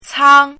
chinese-voice - 汉字语音库
cang1.mp3